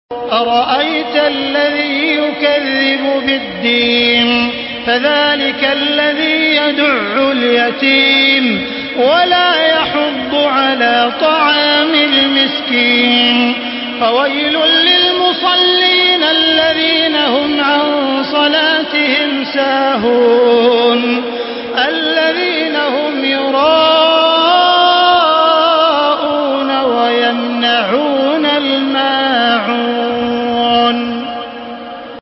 تحميل سورة الماعون بصوت تراويح الحرم المكي 1435
مرتل